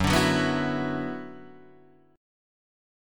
Gb7sus4#5 chord